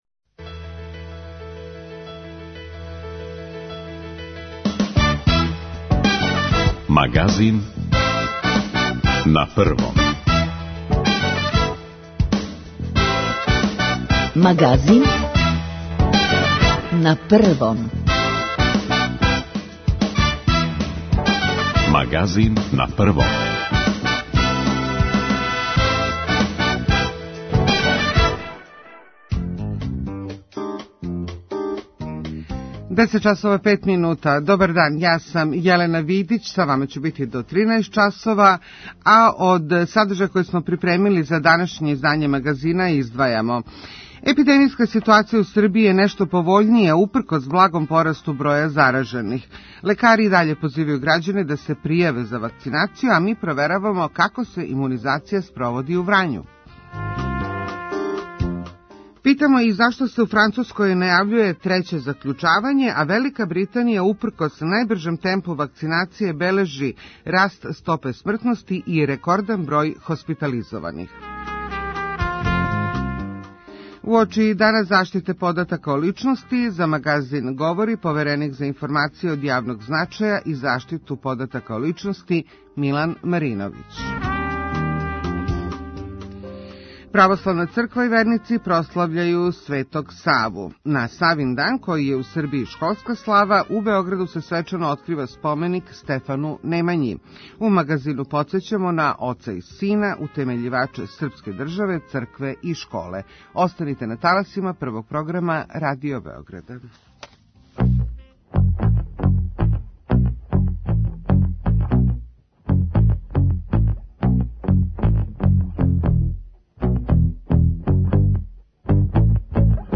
Уочи Дана заштите података о личности гост Магазина је Повереник за информације од јавног значаја и заштиту података о личности Милан Мариновић. На Савиндан, који се слави као школска слава, у Београду се свечано открива споменик Стефану Немањи.